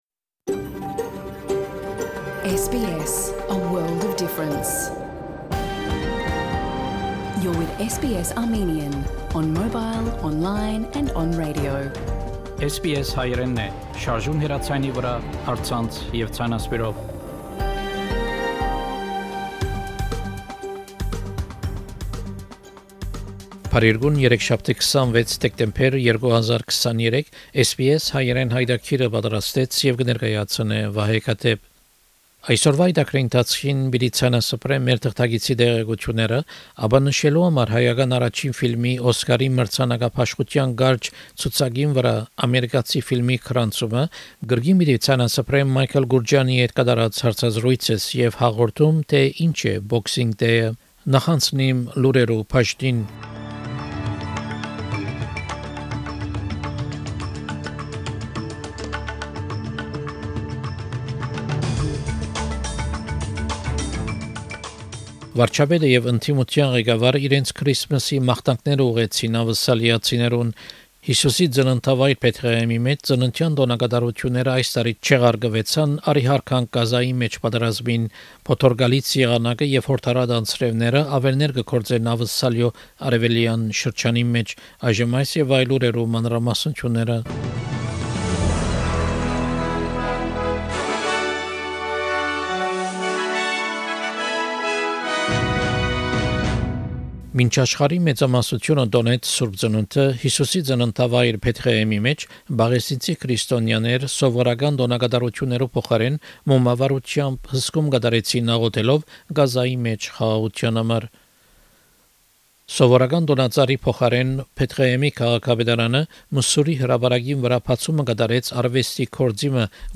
SBS Armenian news bulletin from 26 December program.